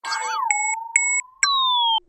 Звук отключения электронного устройства